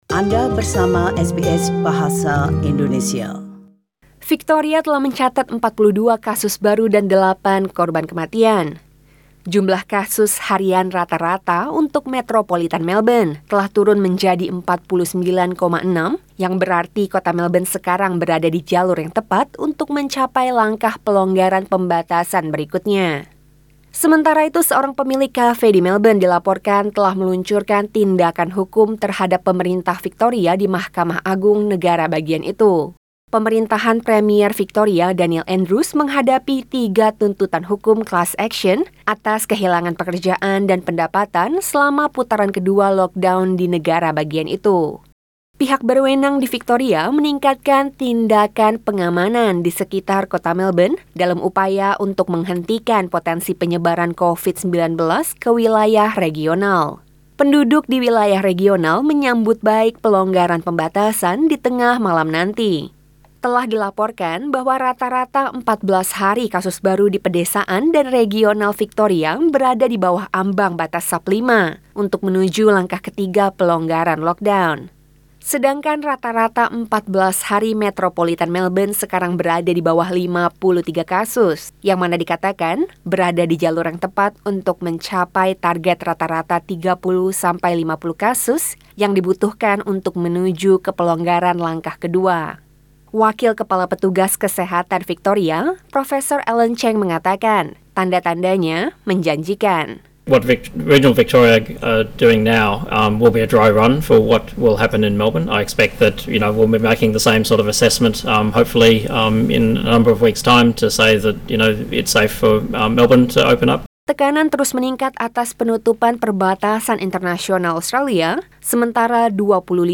SBS Radio News in Bahasa Indonesia – 16 Sep 2020